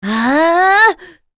Pavel-Vox_Casting5.wav